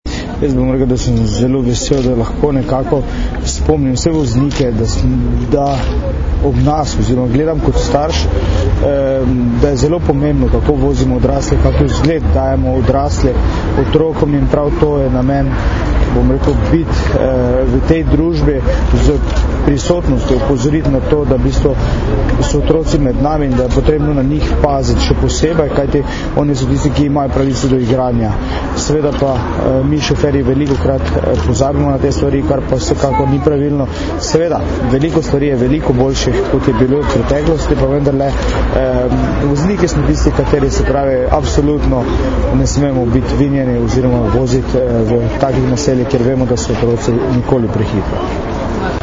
Ministrica za notranje zadeve Katarina Kresal in namestnica generalnega direktorja policije mag. Tatjana Bobnar sta danes, 5. aprila, skupaj z učenci OŠ Oskarja Kovačiča, vrhunskima športnikoma Tino Maze in Dejanom Zavcem ter ljubljanskimi policisti opozorili na varnost otrok v prometu.
Zvočni posnetek izjave Dejana Zavca (mp3)